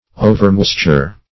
Overmoisture \O"ver*mois"ture\, n. Excess of moisture.